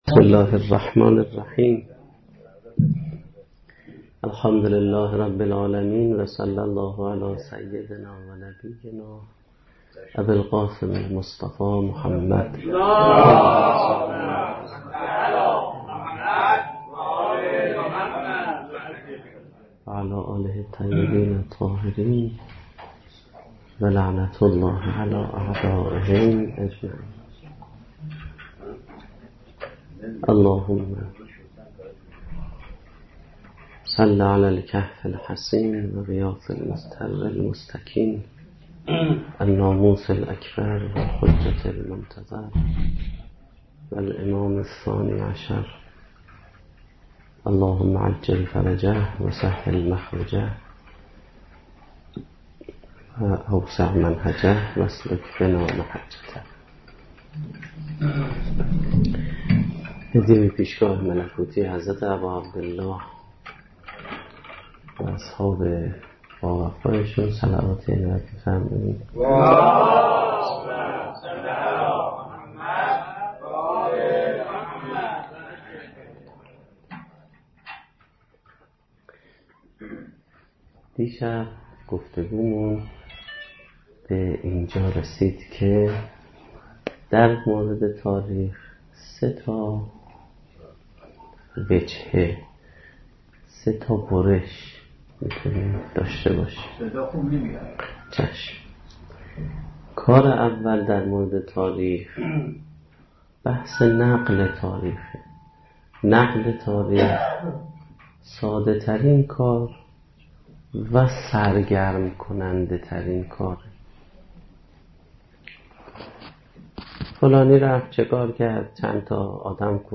سخنرانی
ریشه های فرهنگی حادثه عاشورا - شب سوم محرم الحرام 436